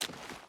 Water Run 4.wav